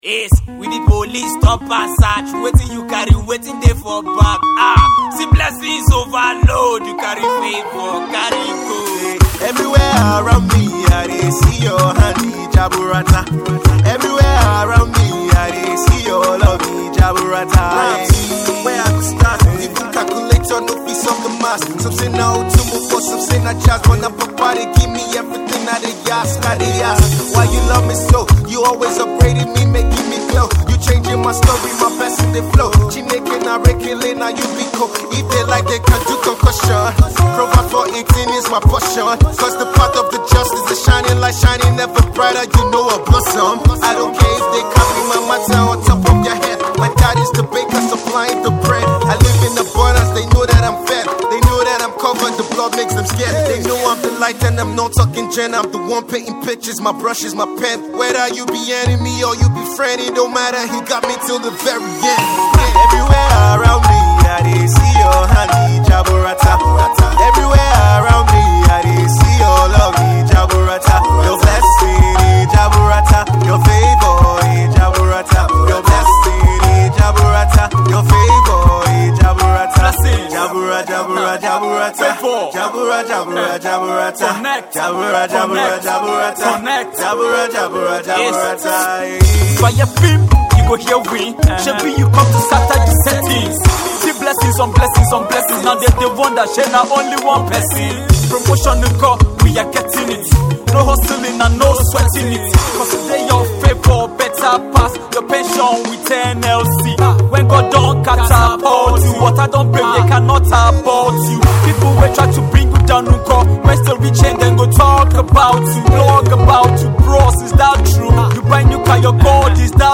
Nigerian gospel song